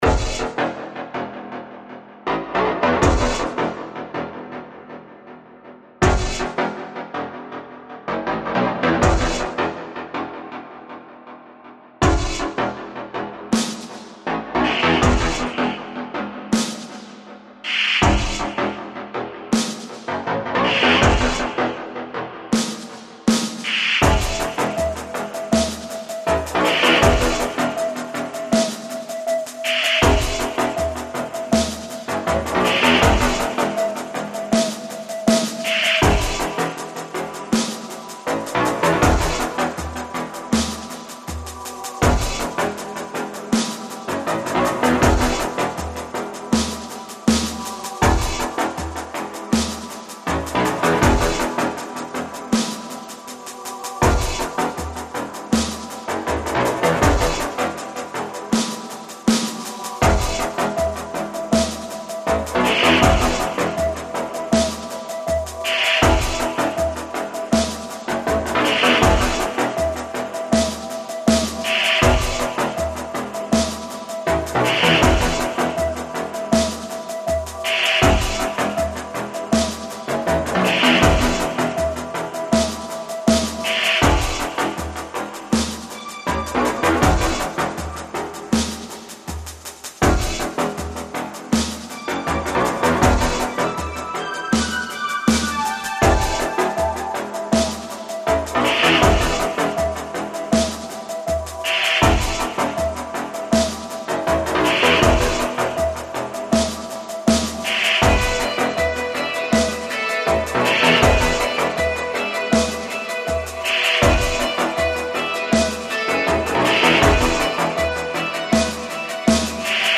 I like the echoing beeps, too.